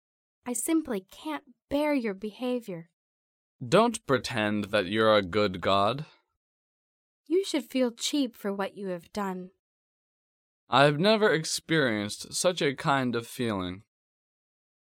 第一， 迷你对话